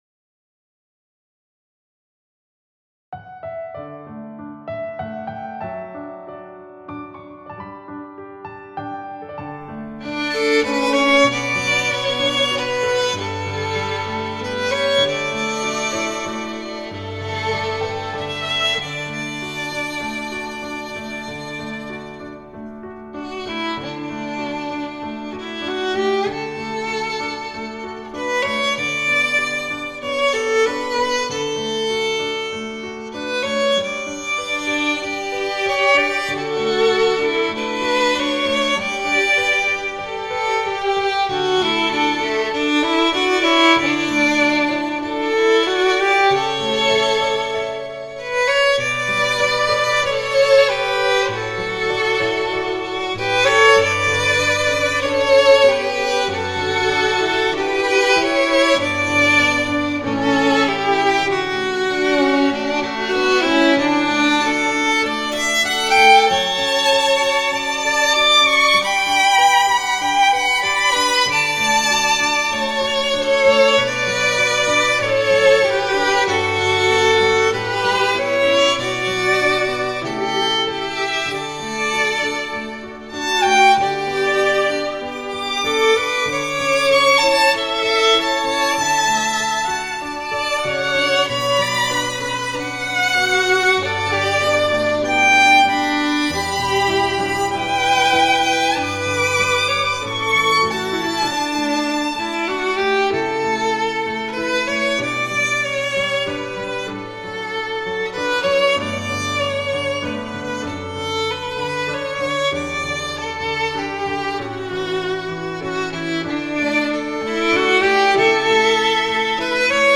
Instrumentation: 2 Violins with Piano accompaniment